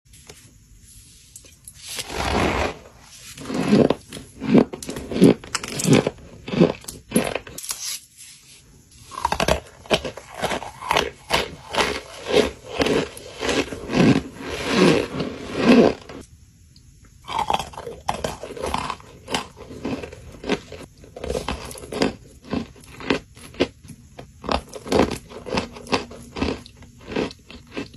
Soft Scrunchy Brick And Pink Sound Effects Free Download